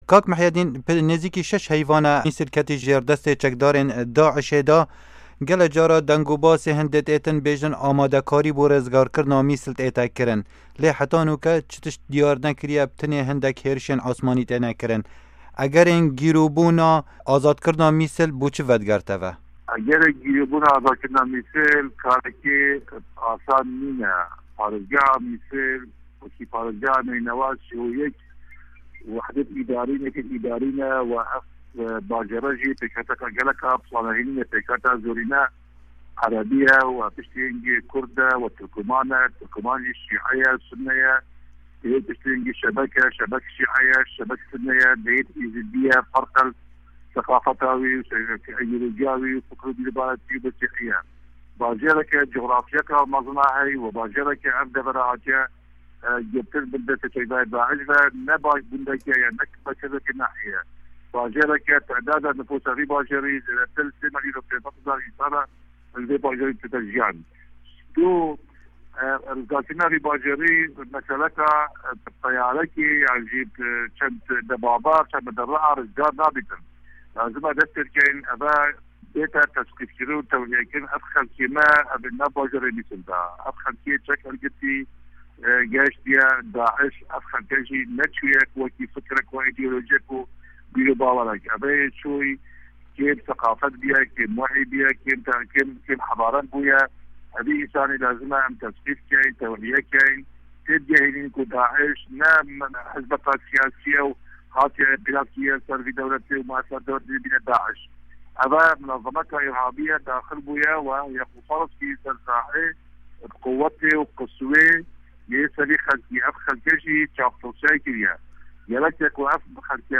hevpeyvin